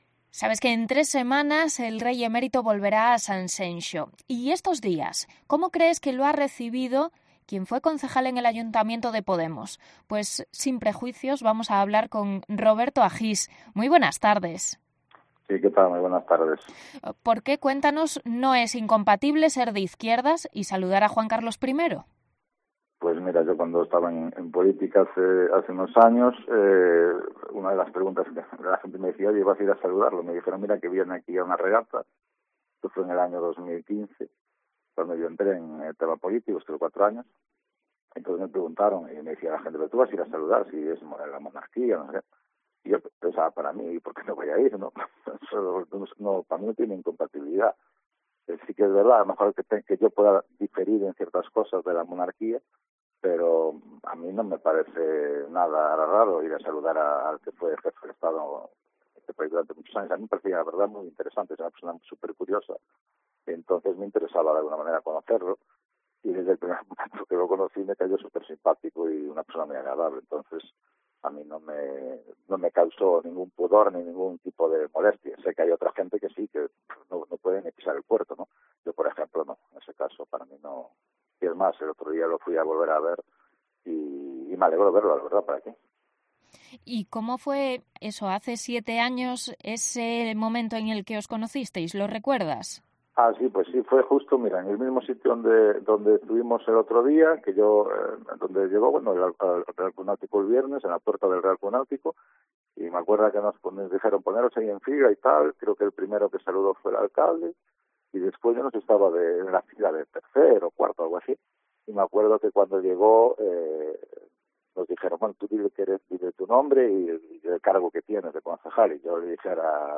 Entrevista con el exconcejal de Sanxenxo Pode, Roberto Agís, sobre su relación con el Rey Emérito